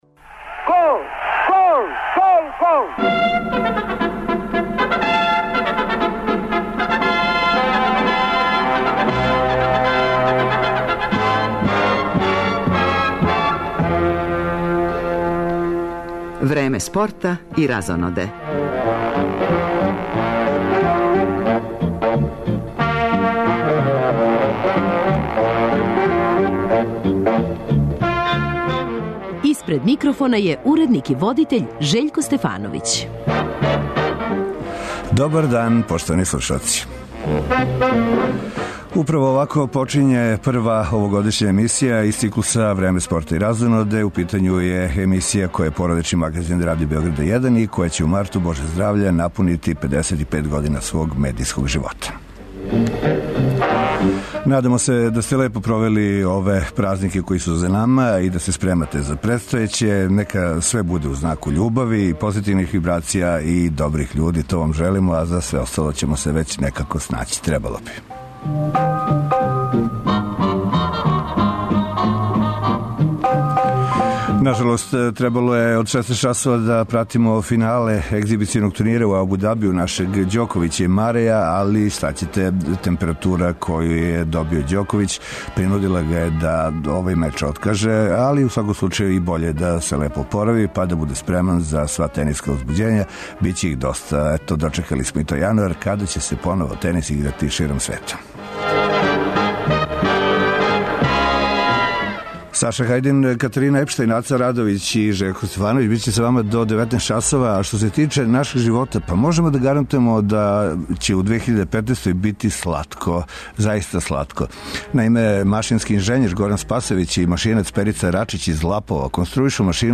Како је последња емисија породичног магазина Радио Београда 1 у прошлој години у потпуности била посвећена успесима наших спортиста, прво овогодишње издање замишљено је као опуштено, разиграно и лагано дружење са гостима из неких других сфера јавног живота.
То не значи да неће бити и спорта - ту је запис са утакмице Лиге шампиона у кошарци између Црвене звезде и Реала, утисци саегзибиционог тениског турнира у коме је у финалу први рекет света, наш Новак Ђоковић, док нам је у рубрици о некадашњим истинским асовима саговорник вечита кошаркашка чигра Зоран Мока Славнић.